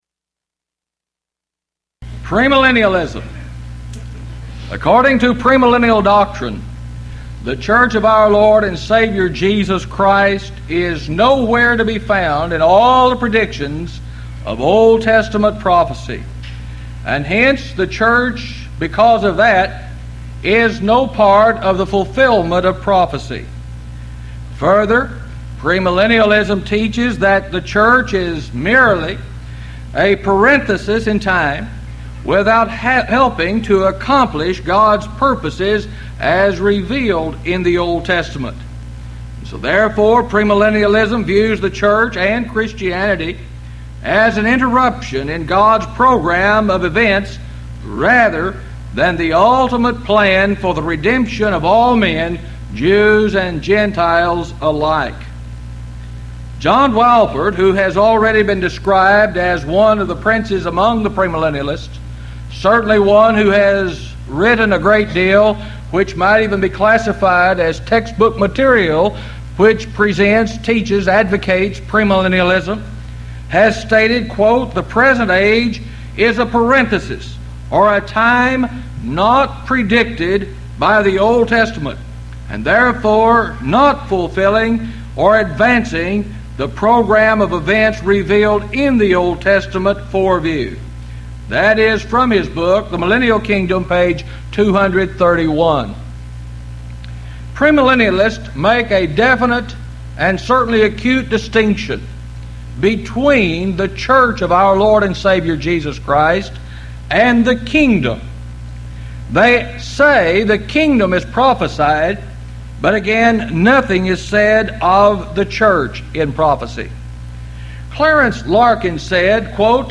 Series: Houston College of the Bible Lectures Event: 1997 HCB Lectures